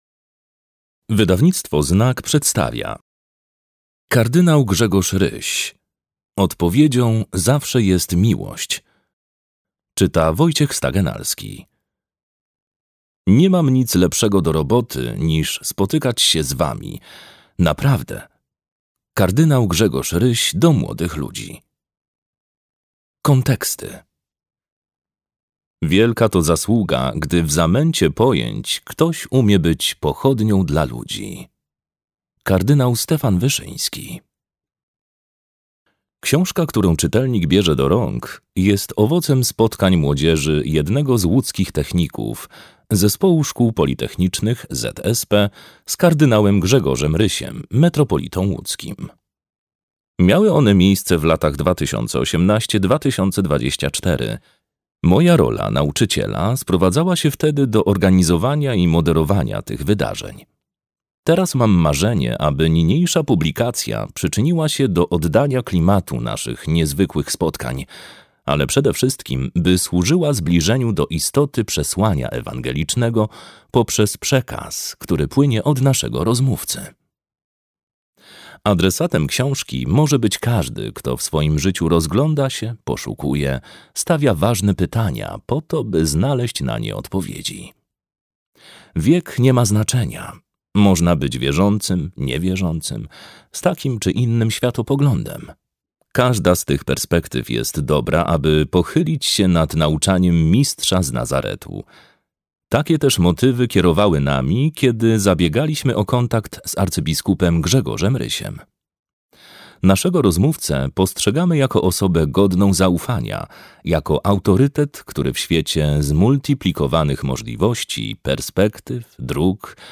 Odpowiedzią zawsze jest miłość - Grzegorz Ryś - audiobook